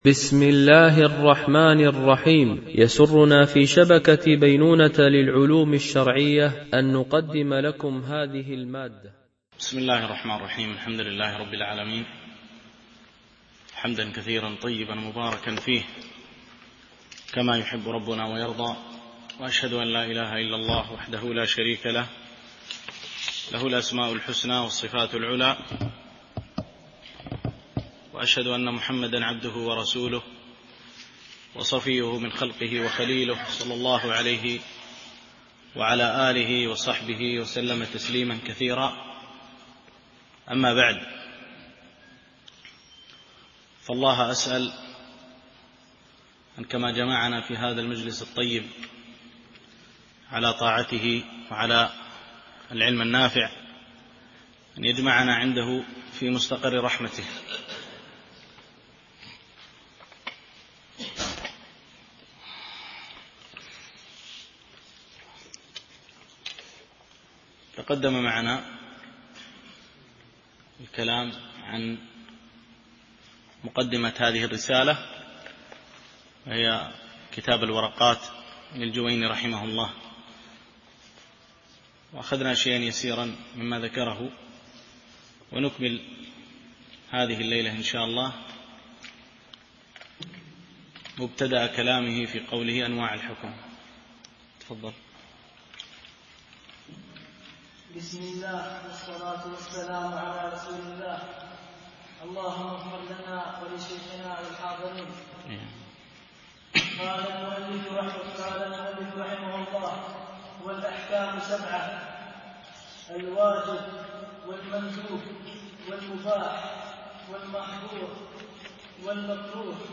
شرح الورقات في أصول الفقه للجويني ـ الدرس 2